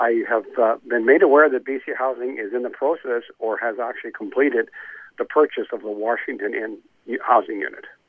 Goat News spoke with Courtenay Mayor Larry Jangula…